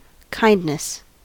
kindness-us.mp3